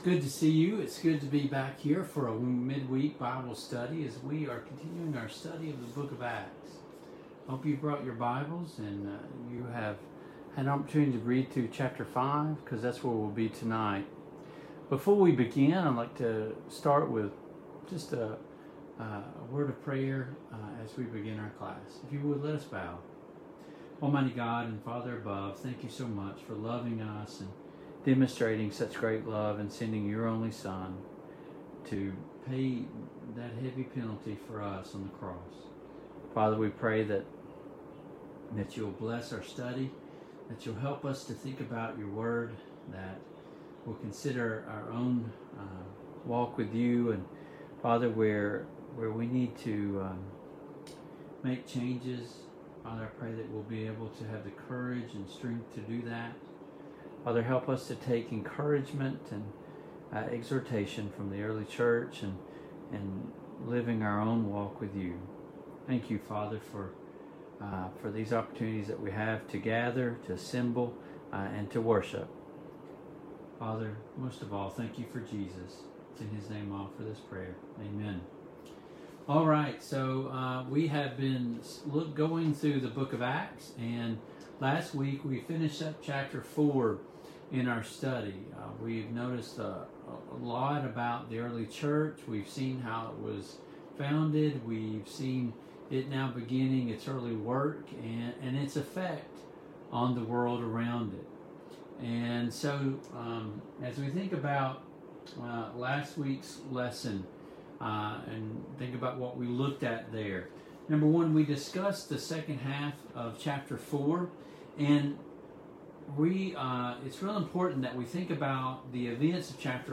Service Type: Online Bible Studies